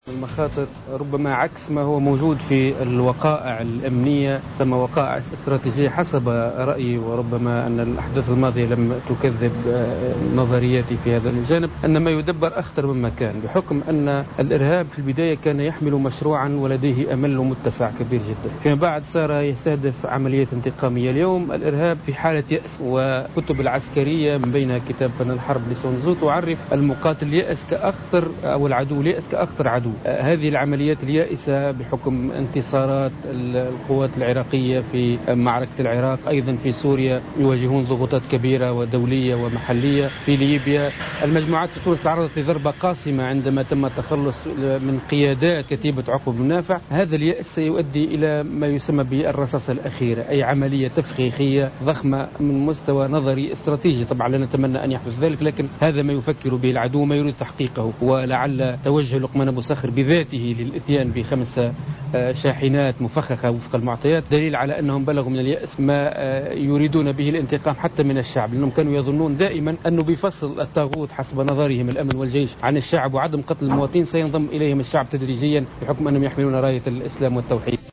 وجاءت هذه التصريحات على هامش يوم دراسي بمقر الإدارة العامة لوحدات التدخل في بوشوشة بالعاصمة تونس بحضور كاتب الدولة لدى وزير الداخلية المكلف بالشؤون المحلية. وأوضح أن الإرهابيين يتحوّلون أكثر خطورة عند مرحلة اليأس وما يعبّر عنه بمرحلة "الرصاصة القاتلة" وما يؤدي الى لجوؤهم إلى العمليات الانتحارية بالأساس وتوجيه الضربات للمدنيين.